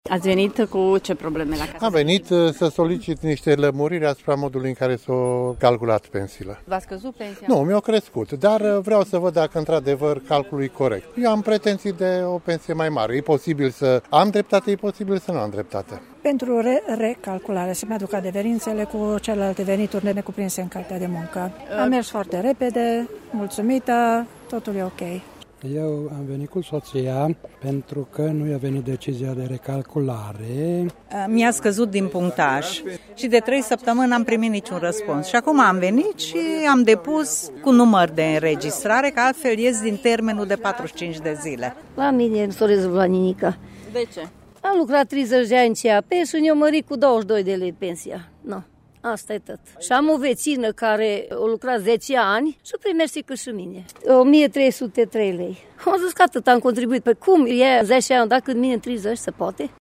Asta ne-au reclamat și nouă astăzi pensionarii veniți în audiențe: